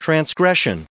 804_transgression.ogg